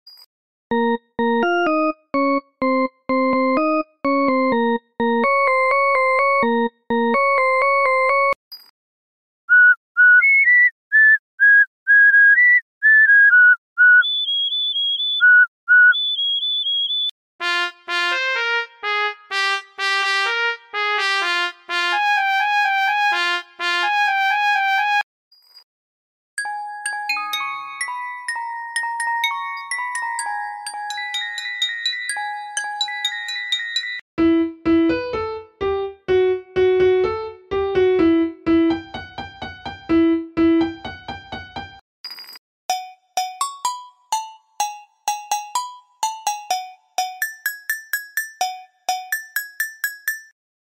instruments